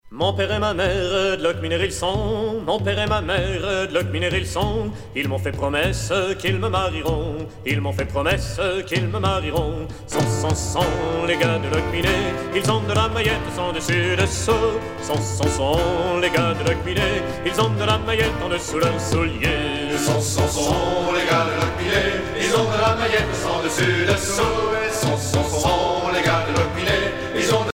danse : rond de Saint-Vincent
Genre laisse
Pièce musicale éditée